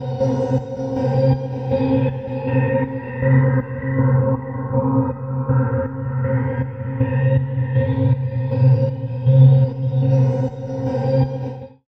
Index of /90_sSampleCDs/Chillout (ambient1&2)/09 Flutterings (pad)
Amb1n2_o_flutter_c.wav